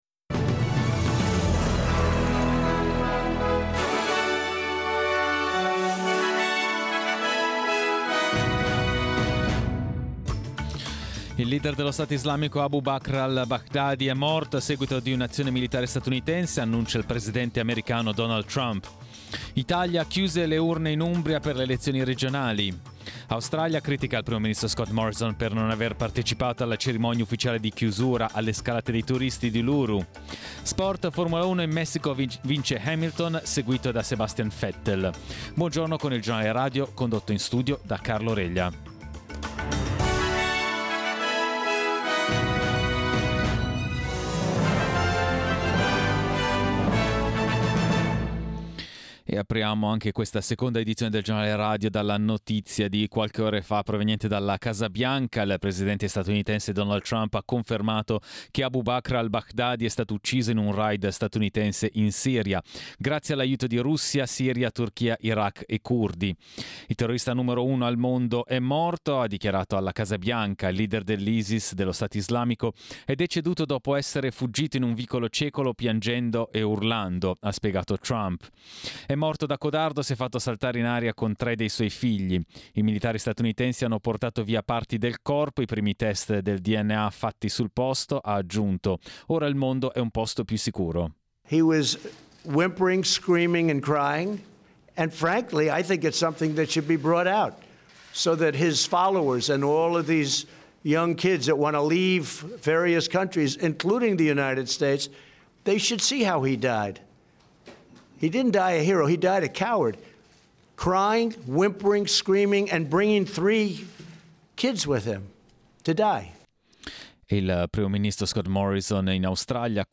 Giornale radio lunedì 28 ottobre